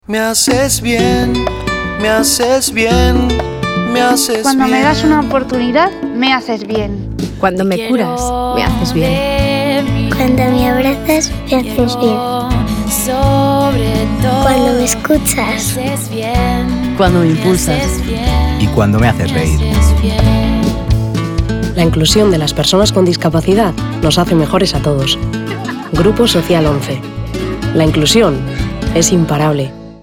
Tanto en los spots de televisión como en las cuñas de radio escuchamos los testimonios de distintas personas con y sin discapacidad, en los que van haciendo distintas afirmaciones (“cuando me das una oportunidad...”, “cuando me curas”, “cuando me escuchas”, “cuando me abrazas”, “cuando me emocionas”, “cuando me enseñas”, “cuando me impulsas”, “cuando me quieres y me haces reír”,....), todas ellas replicadas con la misma declaración: “Me haces bien”.
SPOTS RADIO
Voz masculina